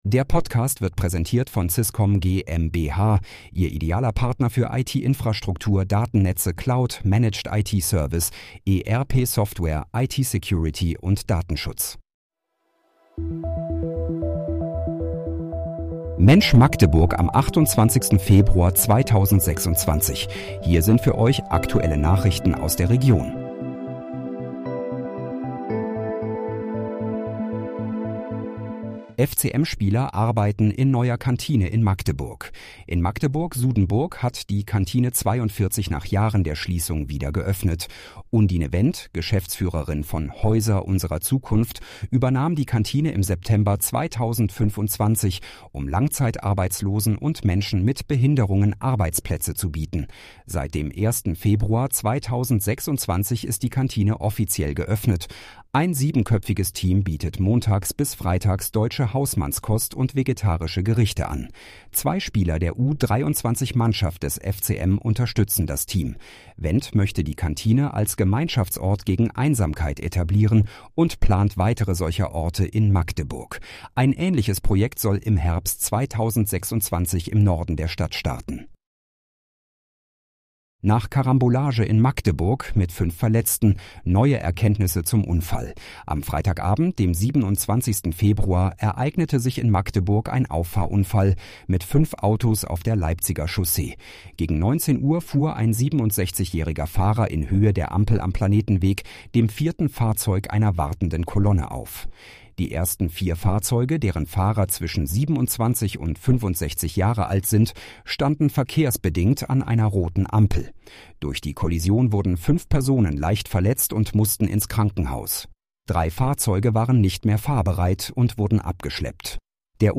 Mensch, Magdeburg: Aktuelle Nachrichten vom 28.02.2026, erstellt mit KI-Unterstützung